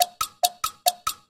squeaky_tick_friend_01.ogg